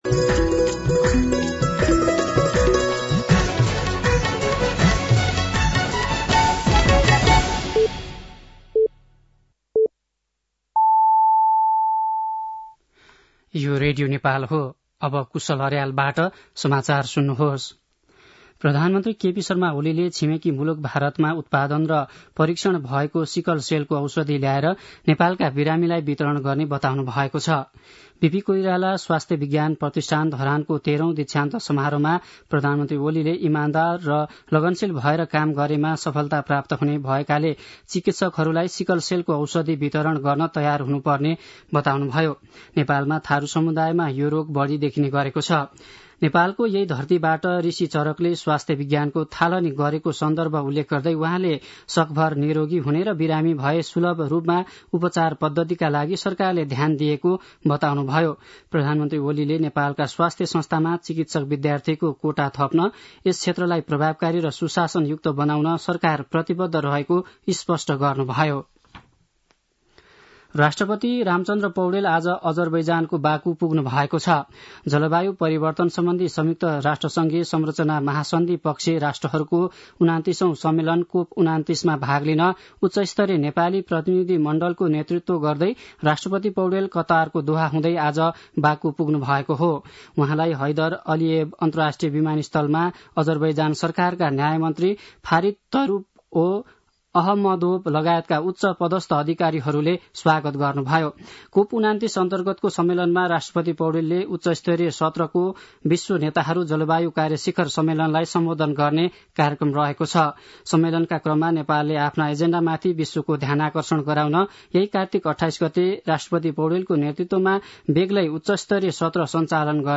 साँझ ५ बजेको नेपाली समाचार : २७ कार्तिक , २०८१